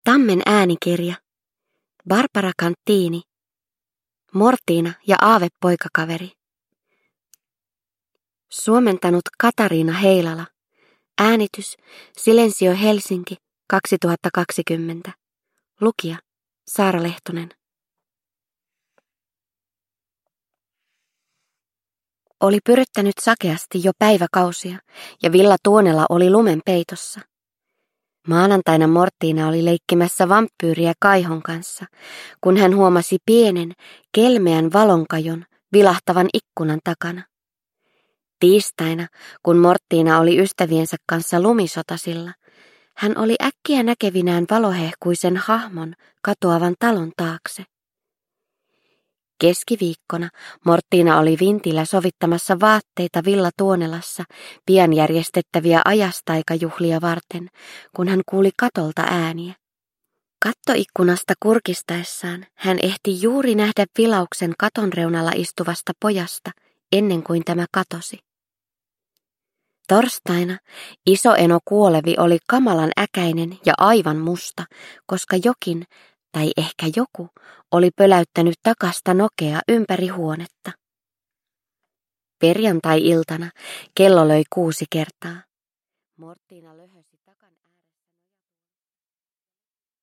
Mortina ja aavepoikakaveri – Ljudbok – Laddas ner